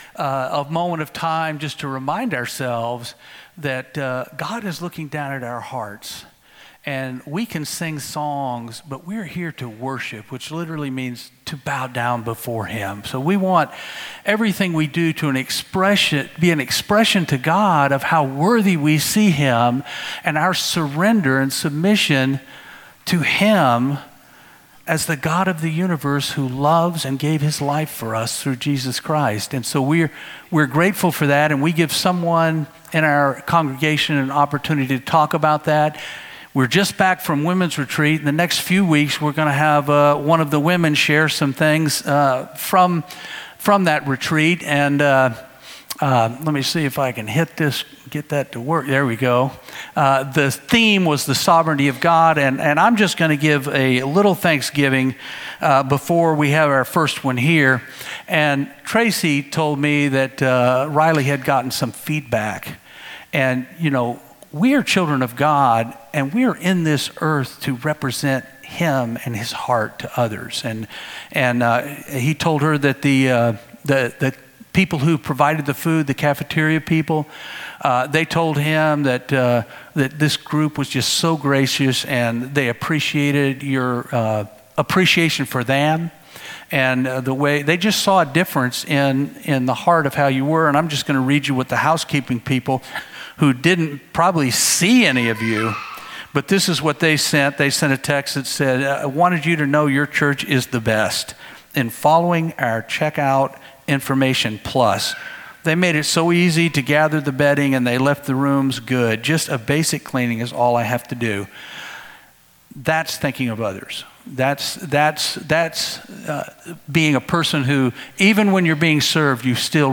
Testimony